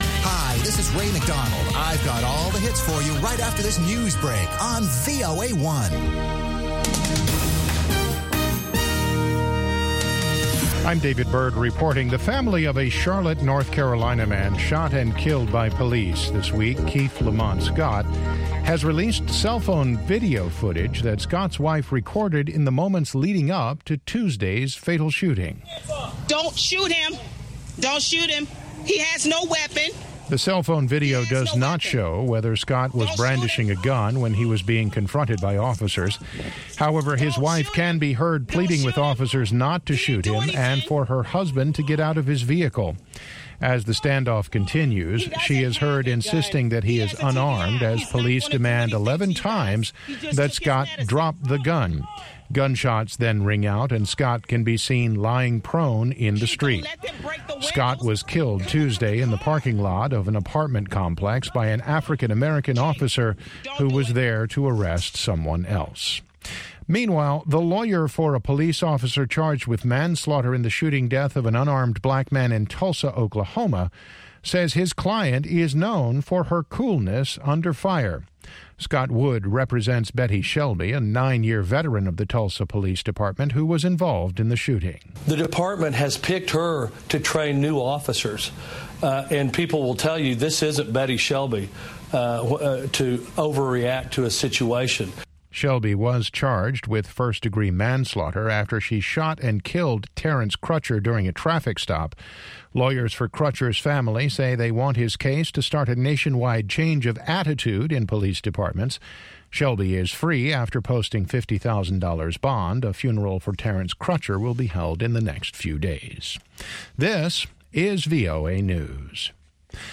اخبار